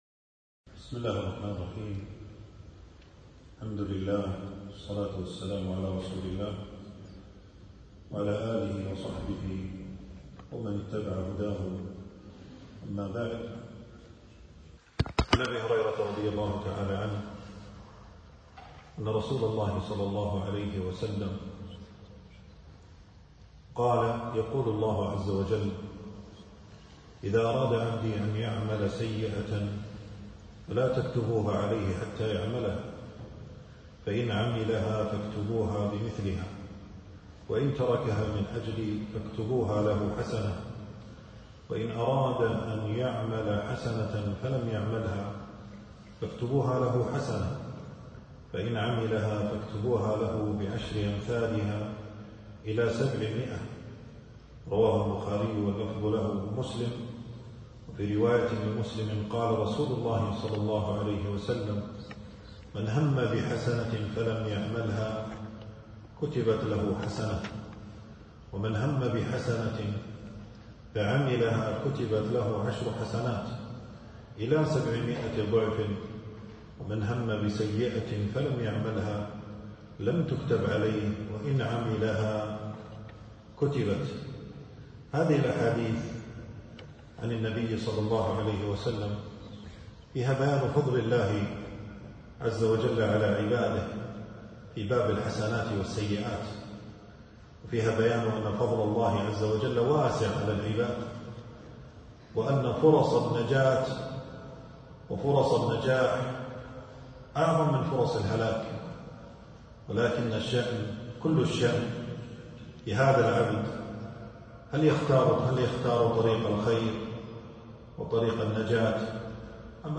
تنزيل تنزيل التفريغ كلمة بعنوان: بيان أن فضل الله واسع على العباد وأن الحسنة بعشر أمثالها.
في مسجد أبي سلمة بن عبدالرحمن.